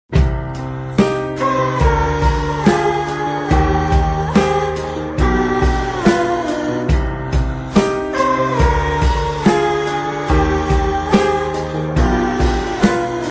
Damage coda Meme Effect sound effects free download